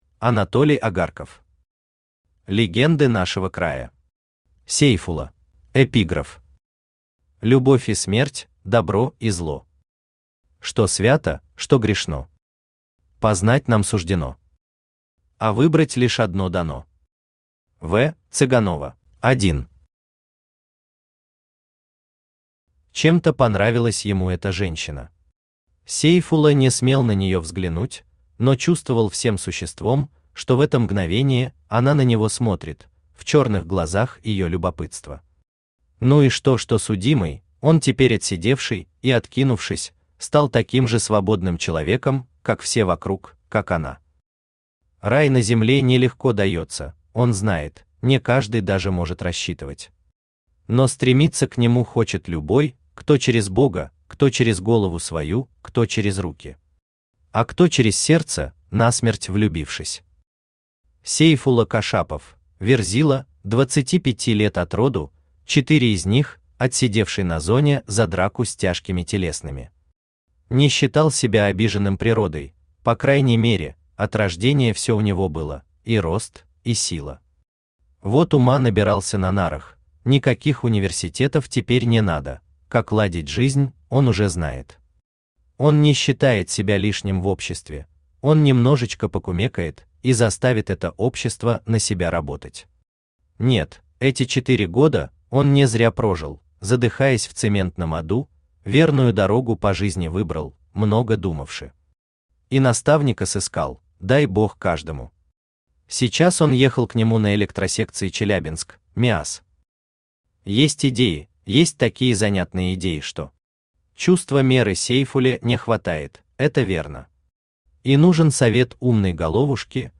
Аудиокнига Легенды нашего края. Сейфула | Библиотека аудиокниг
Сейфула Автор Анатолий Агарков Читает аудиокнигу Авточтец ЛитРес.